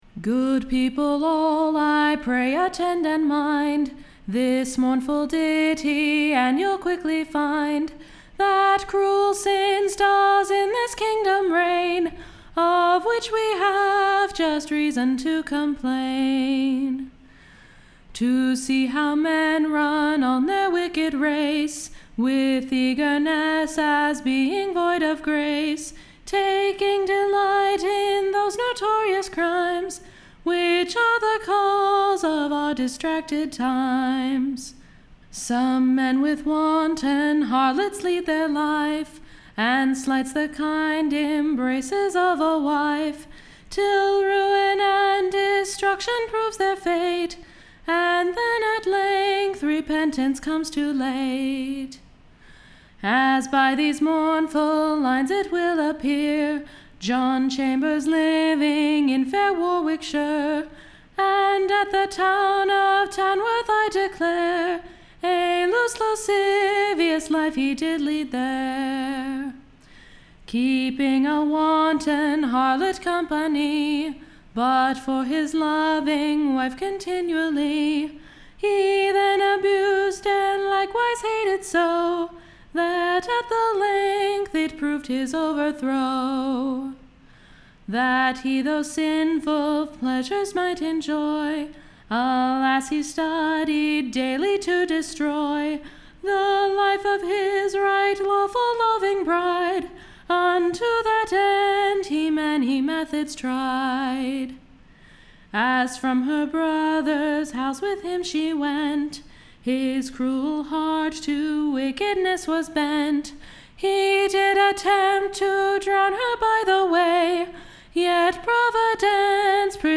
Execution Ballads